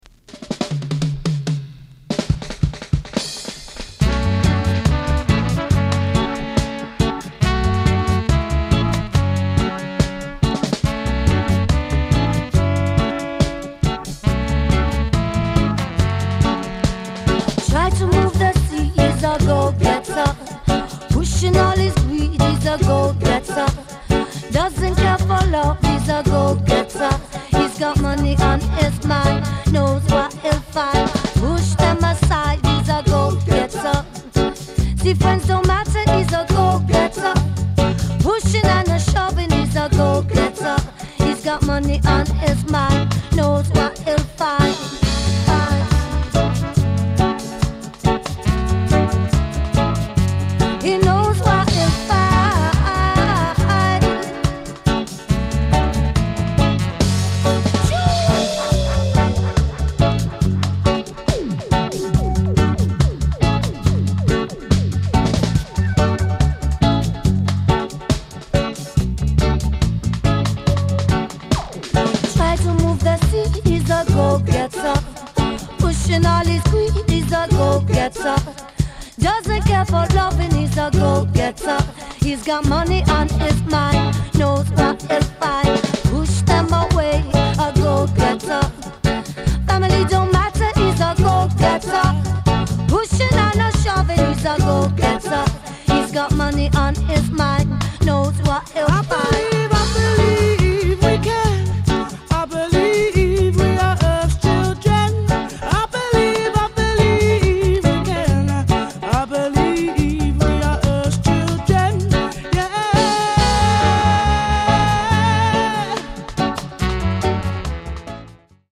this is UK reggae!